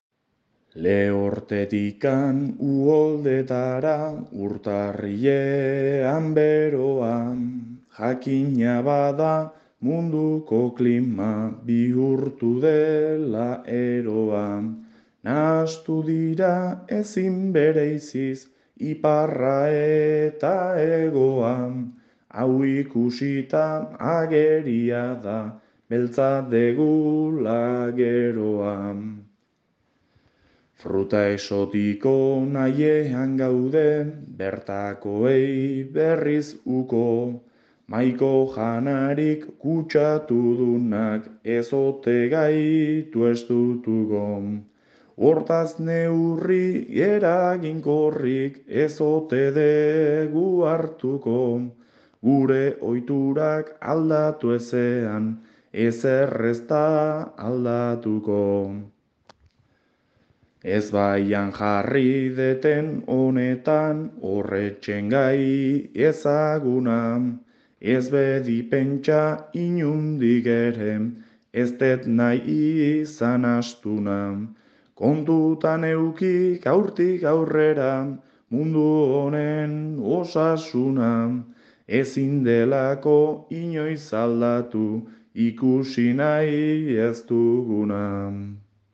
bertso sorta